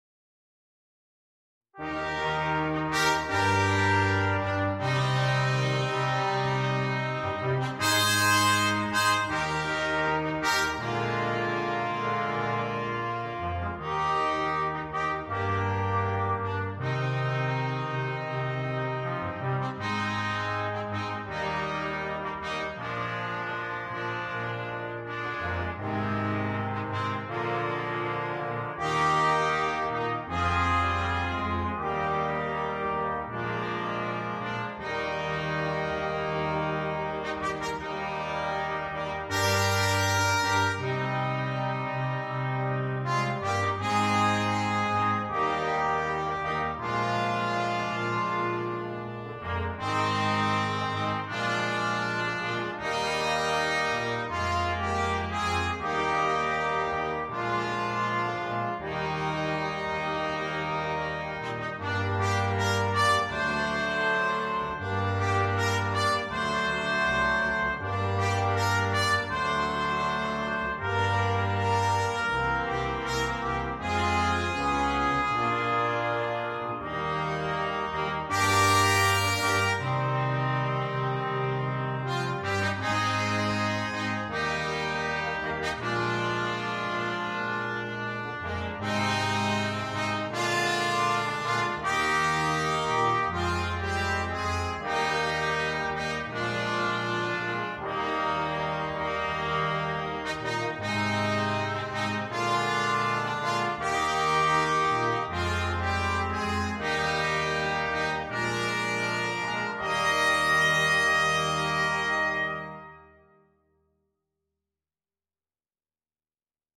на брасс-квинтет